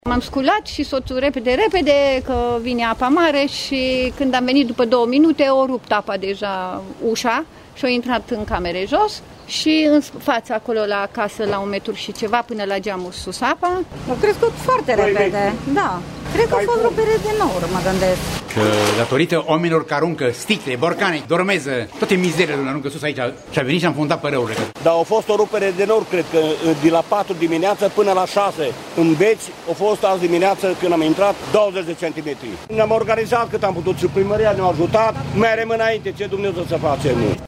Sătenii spun că e și vina oamenilor, care aruncă în râul Gurghiu tot felul de deșeuri: